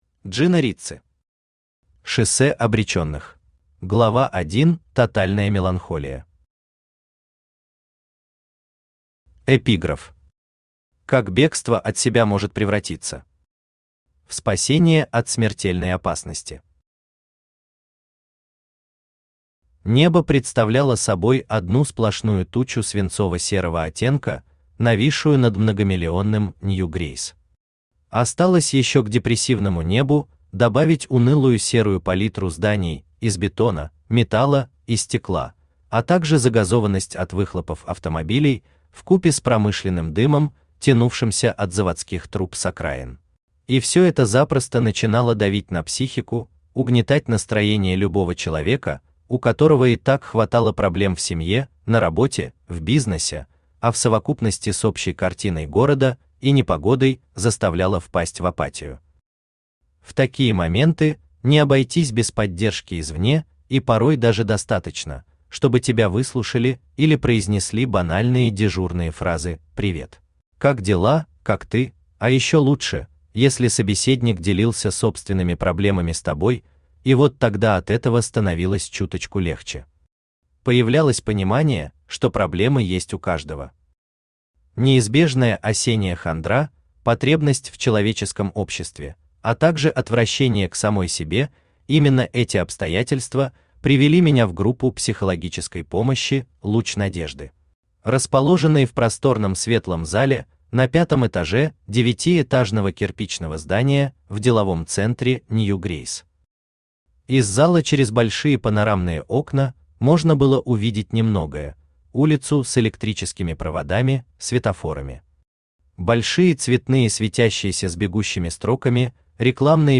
Аудиокнига Шоссе обреченных | Библиотека аудиокниг
Aудиокнига Шоссе обреченных Автор Джина Рицци Читает аудиокнигу Авточтец ЛитРес.